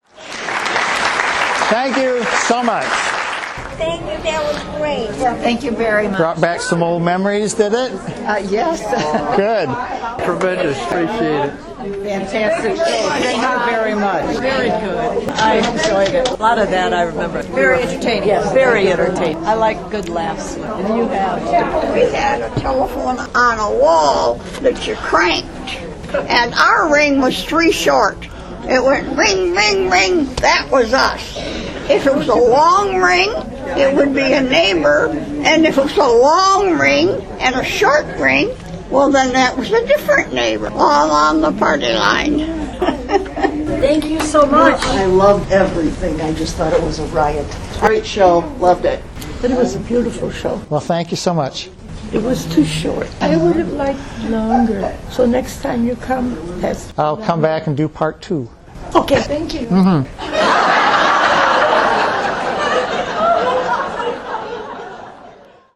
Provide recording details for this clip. DeKalbAudience.mp3